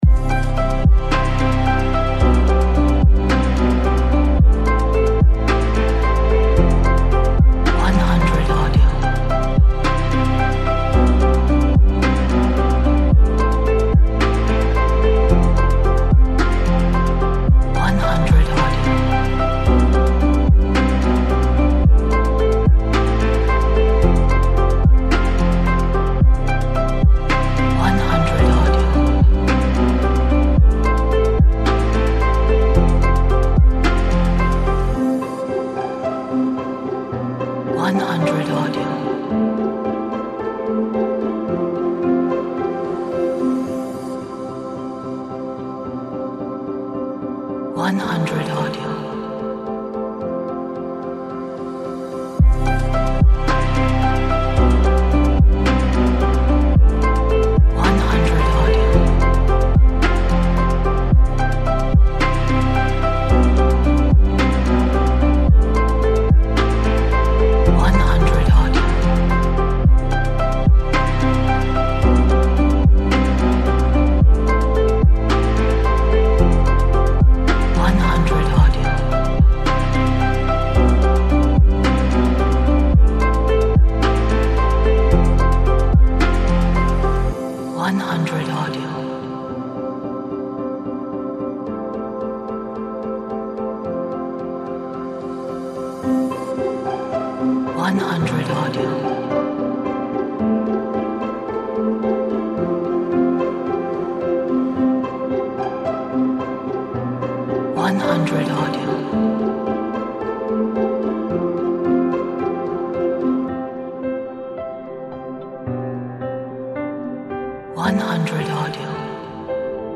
温暖 柔和 生长 希望 爱